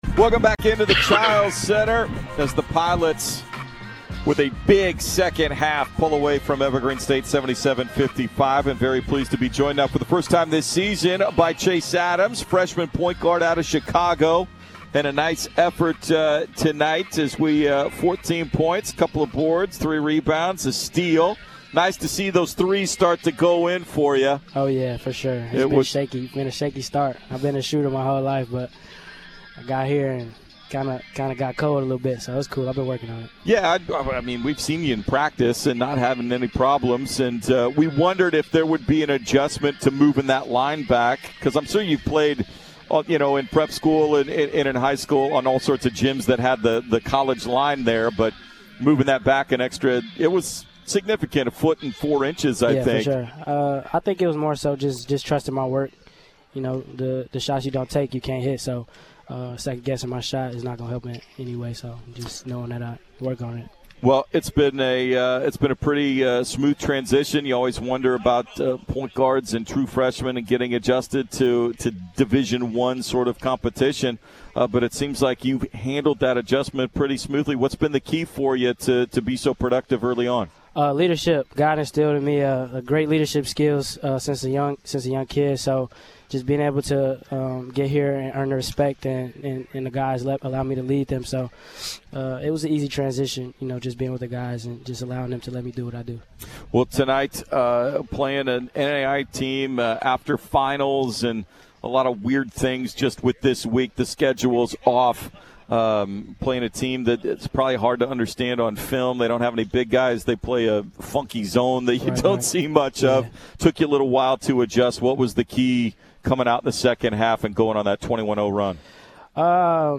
Men's Hoops Post-Game Interviews vs. Evergreen